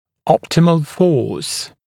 [‘ɔptɪməl fɔːs][‘оптимэл фо:с]оптимальная сила